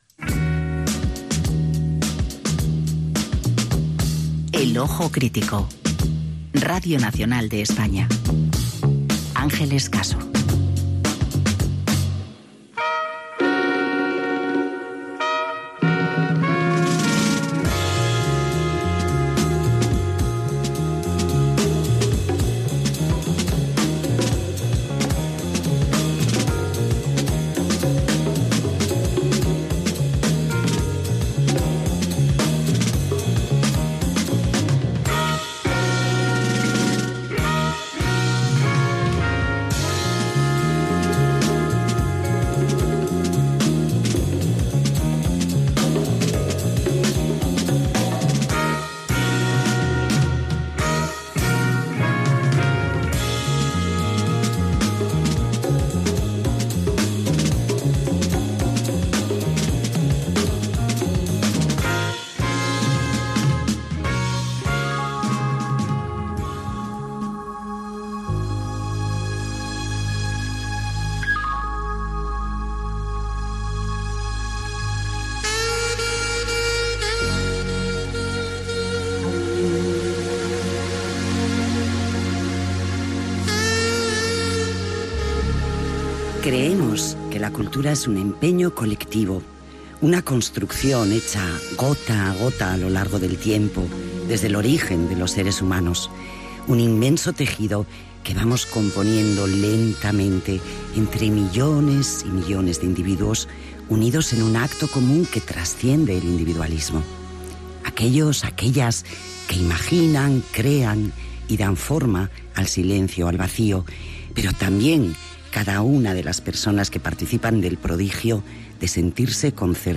Careta, declaració de principis sobre la Cultura, objectius del programa, avís del canvi d'horari i de dia i equip
FM